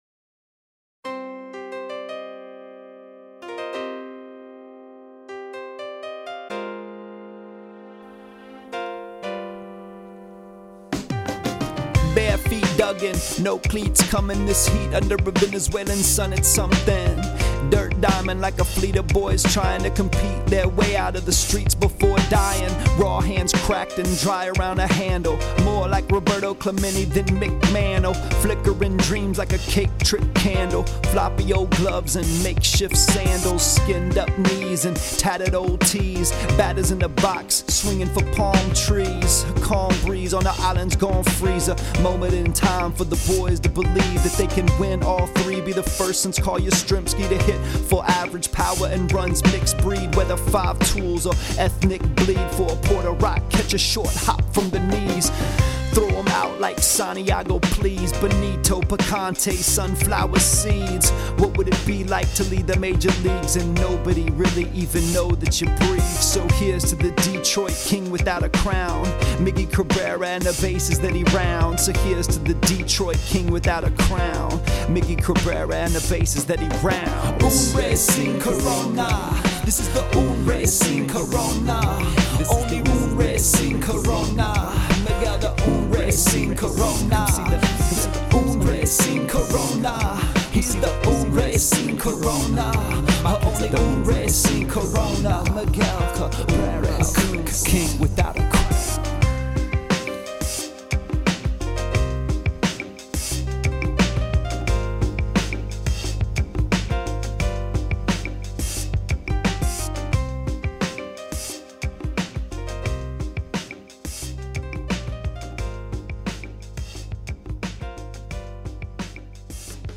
I know you’d probably prefer a blong about Social Security administrative law or the rise and fall of sharecropping or why cuts bleed than to hear a song about baseball.